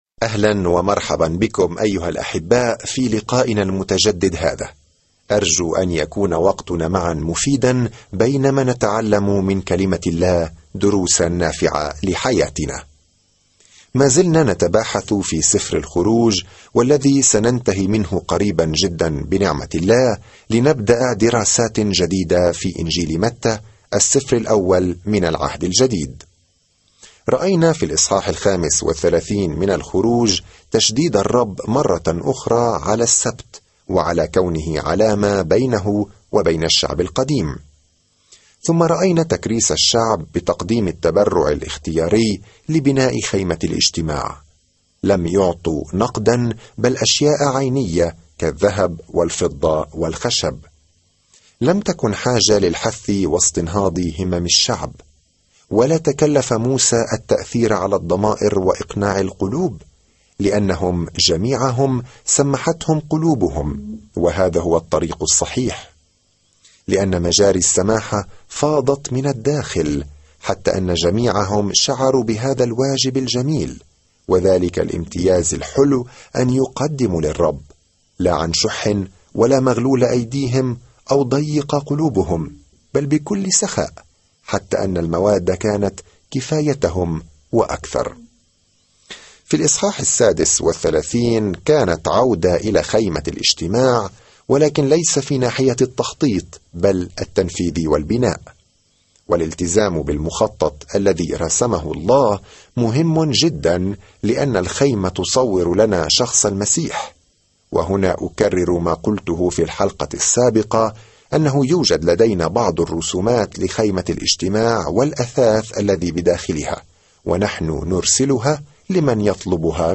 الكلمة اَلْخُرُوجُ 10:37-29 اَلْخُرُوجُ 1:38-27 يوم 31 ابدأ هذه الخطة يوم 33 عن هذه الخطة يتتبع سفر الخروج هروب إسرائيل من العبودية في مصر ويصف كل ما حدث على طول الطريق. سافر يوميًا خلال سفر الخروج وأنت تستمع إلى الدراسة الصوتية وتقرأ آيات مختارة من كلمة الله.